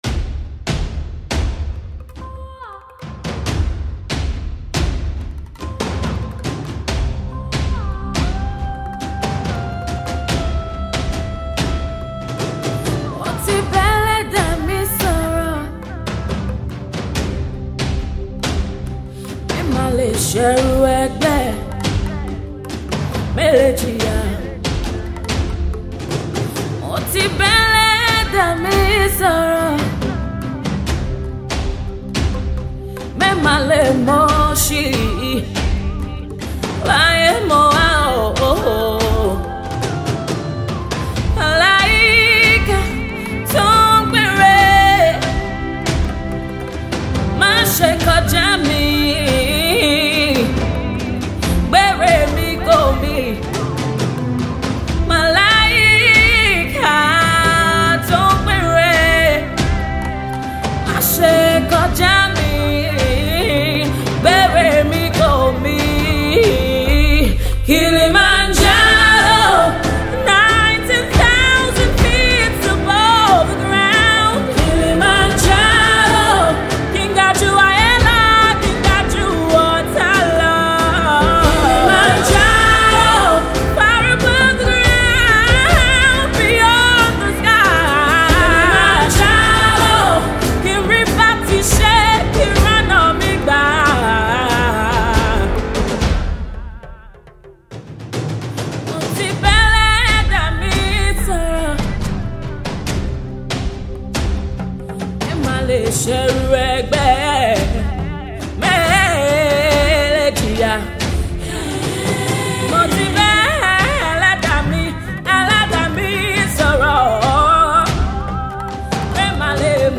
A mix of soul, Afro-pop and RnB.